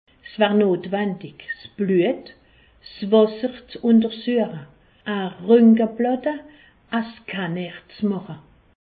Haut Rhin
Ville Prononciation 68
Pfastatt